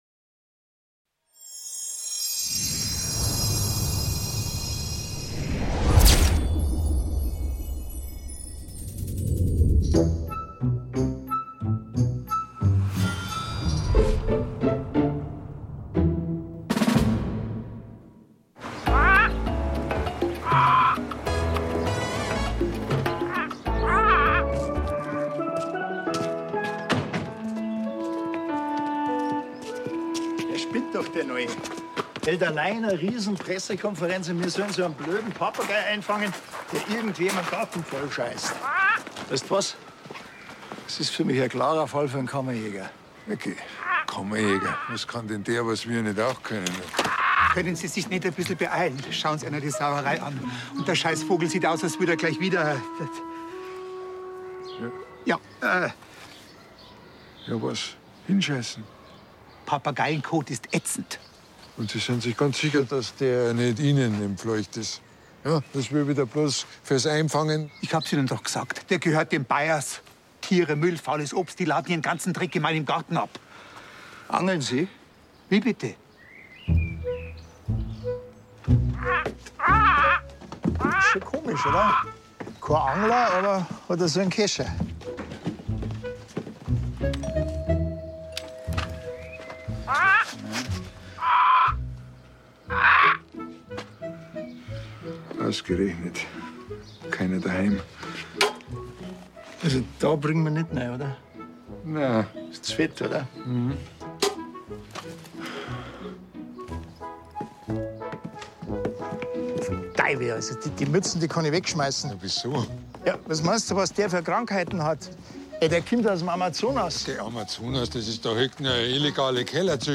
S01E01: Kleine Fische, große Fische - Hubert und Staller Hörspiel